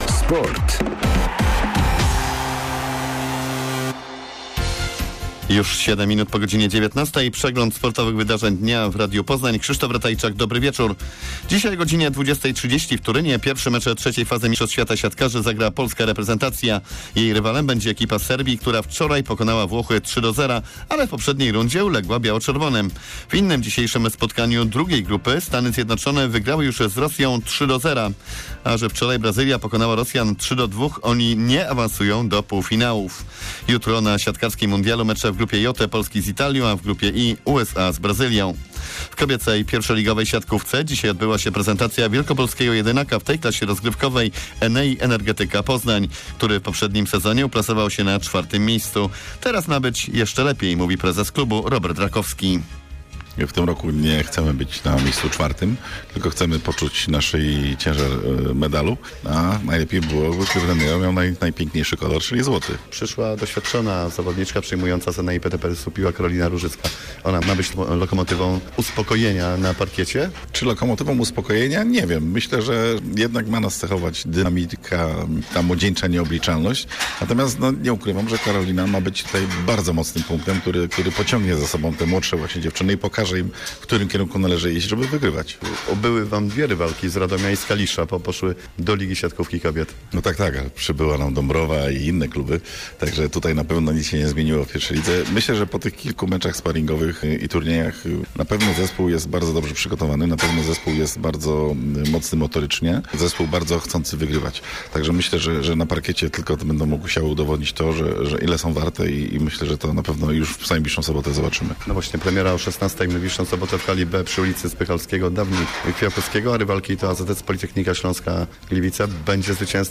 27.09 serwis sportowy godz. 19:05